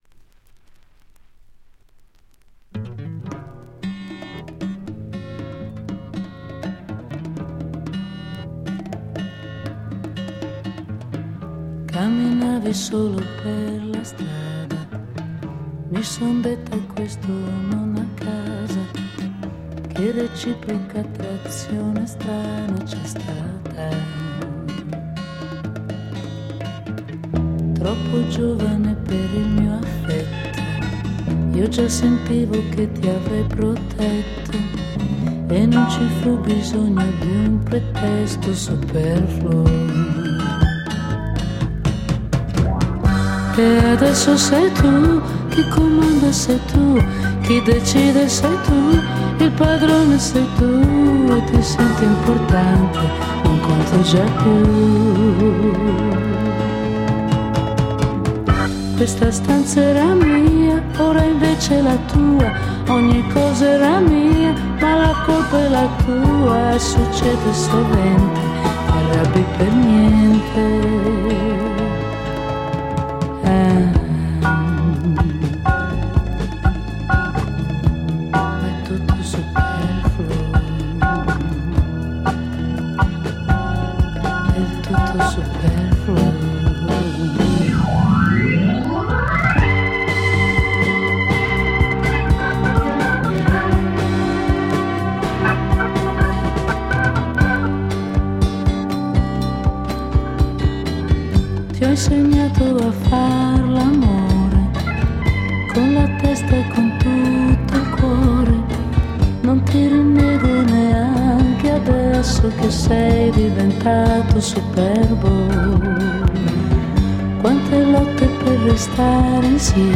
Italian Female 70s groove single